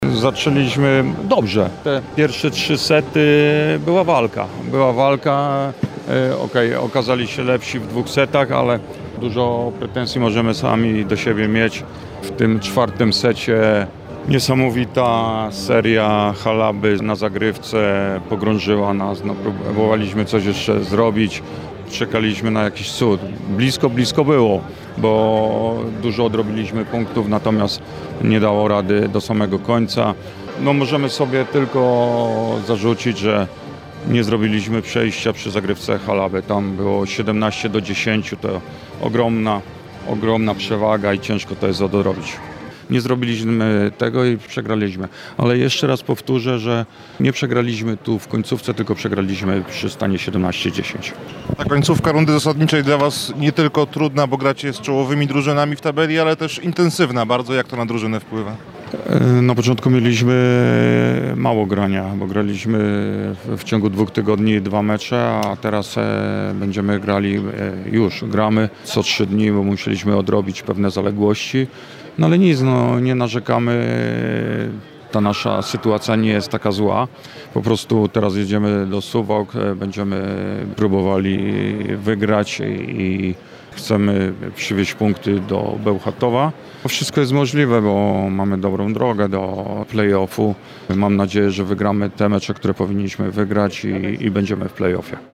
– analizował trener Skry, Krzysztof Stelmach.